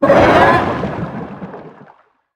Sfx_creature_pinnacarid_rideon_01.ogg